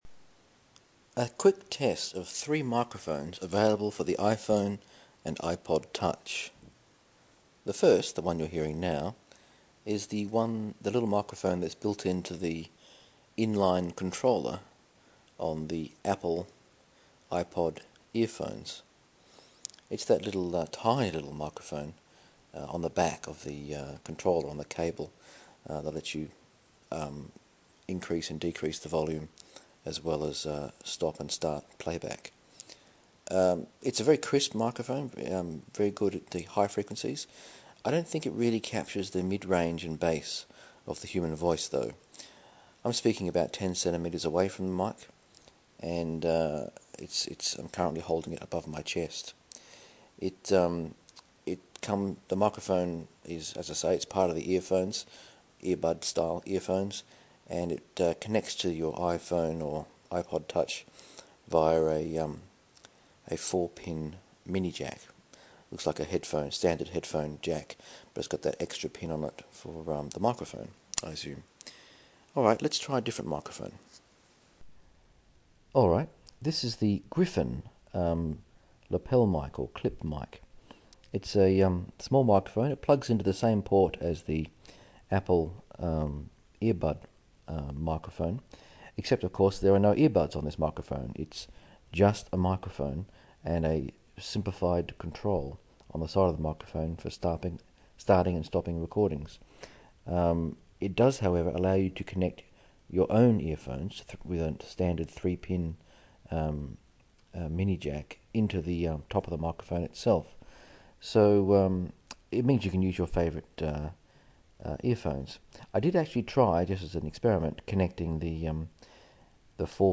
Three Mics Tested - Apple, Griffin, and Belkin.
38400-three-mics-tested-apple-griffin-and-belkin.mp3